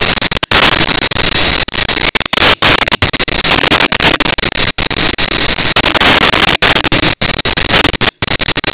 laughb.wav